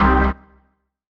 ORGAN-03.wav